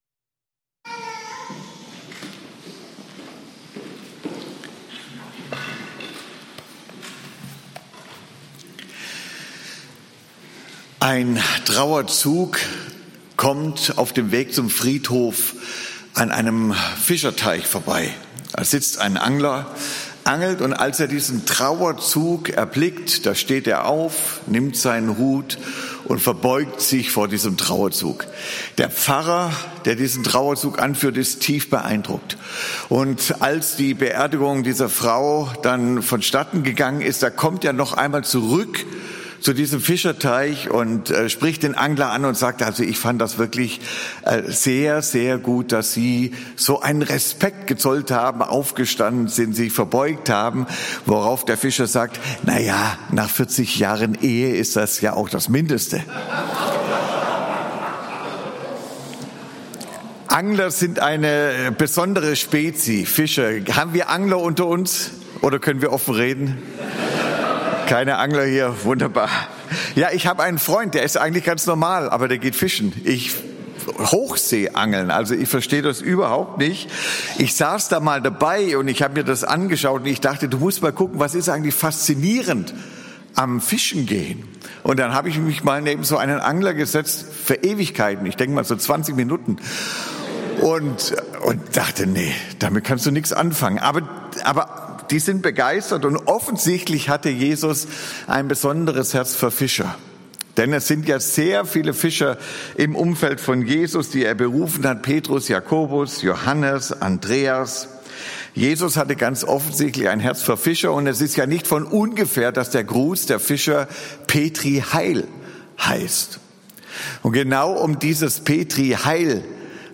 EFG-Haiger Predigt-Podcast Petri heil - Hoffnung konkret Play Episode Pause Episode Mute/Unmute Episode Rewind 10 Seconds 1x Fast Forward 30 seconds 00:00 / 33:08 Abonnieren Teilen RSS Feed Teilen Link Embed